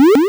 Powerup30.wav